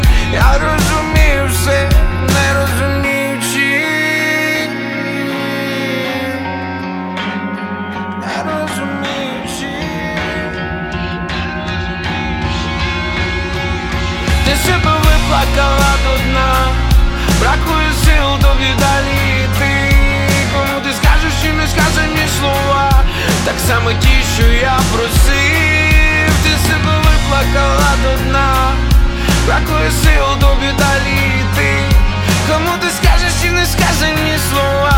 Жанр: Рэп и хип-хоп / Иностранный рэп и хип-хоп / Украинские